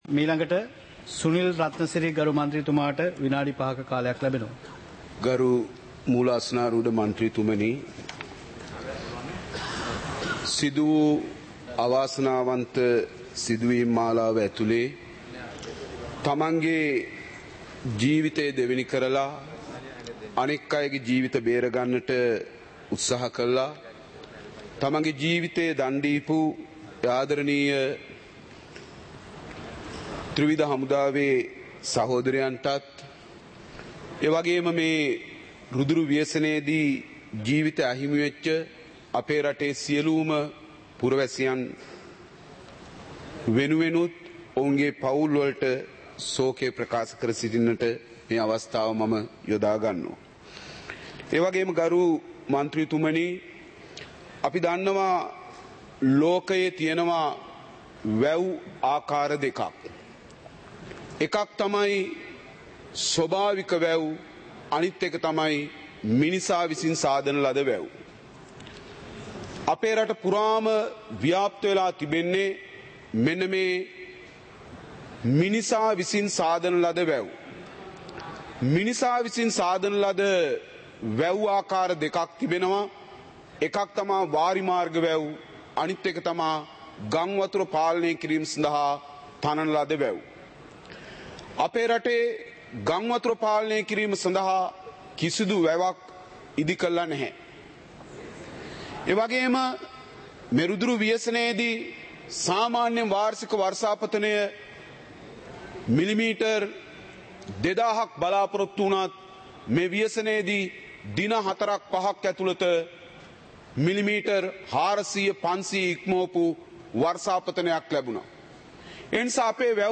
சபை நடவடிக்கைமுறை (2025-12-05)